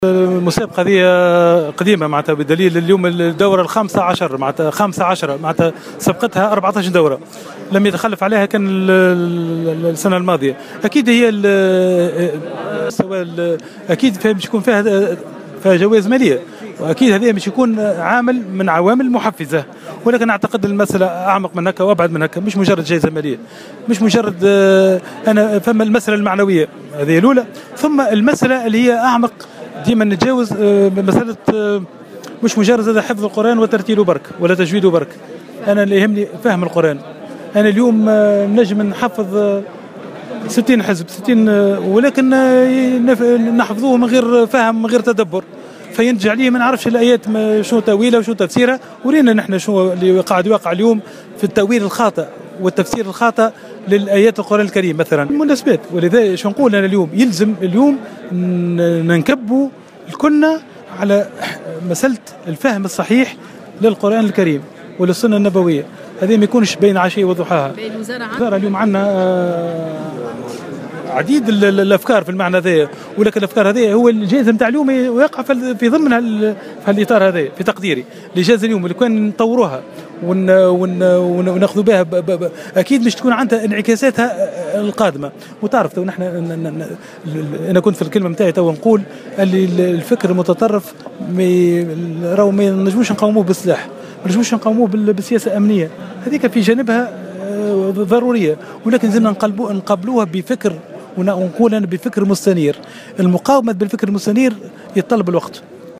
أكد وزير الشؤون الدينية أحمد عظوم اليوم الاثنين في تصريحات صحفية أنه من المهم عدم الاقتصار على حفظ القرآن دون فهمه أوتدبيره.
وأضاف أن الفكر المتطرف لا يُقاوم بالسلاح والسياسية الأمنية فقط بل أيضا بالفكر المستنير الذي يتطلب وقتا. وجاءت تصريحات الوزير على هامش فعاليّات تونس العالمية لحفظ القرآن وتجويده في تونس العاصمة.